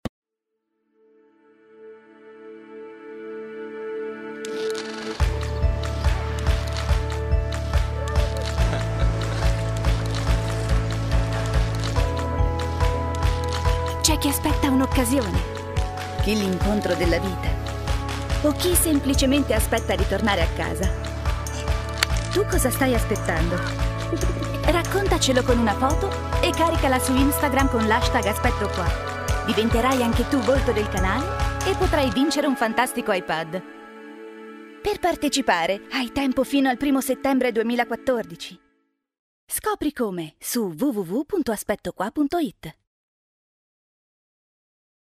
Speaker italiana, voce giovane e versatile, voci bambini e caratterizzazioni. Redazione testi e registrazioni in 24 ore da Home Studio.
Sprechprobe: Werbung (Muttersprache):